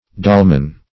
Dolman \Dol"man\ (d[o^]l"man), n.; pl. Dolmans.